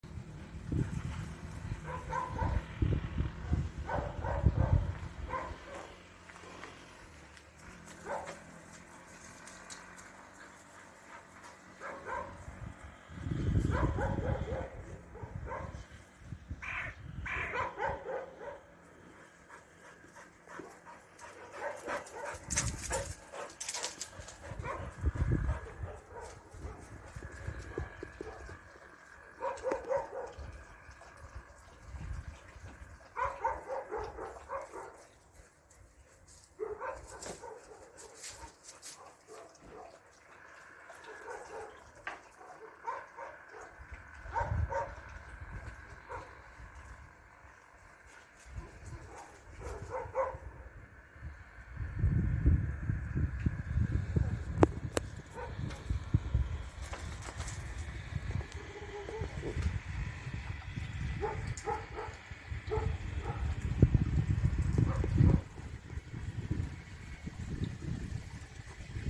לאקי וחברה בחצר לקראת הסוף
לאקי רועה בקר אוסטרלי וחברה מלינואה משחקים